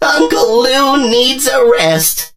lou_die_vo_05.ogg